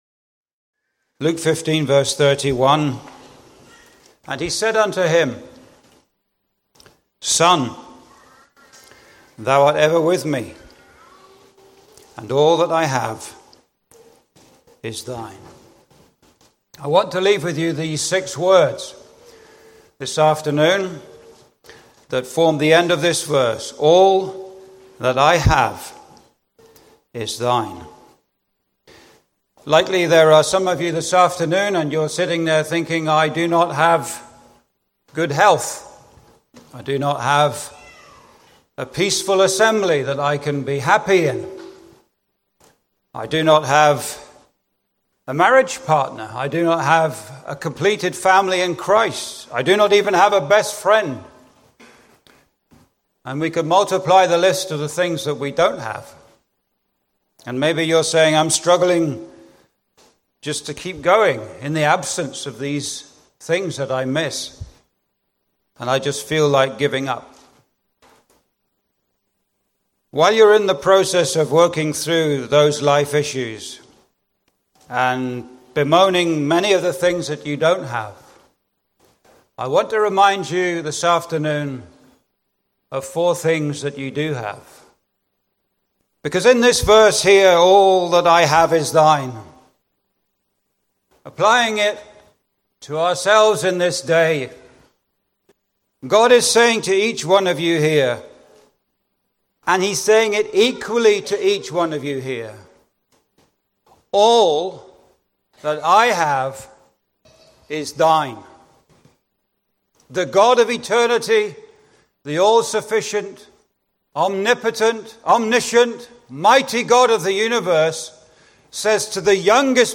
(Recorded at the Roseisle Conference, 4th Oct 2025)
Doctrinal messages